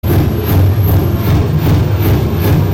Live from BuddyFest: Buddyfest (Audio)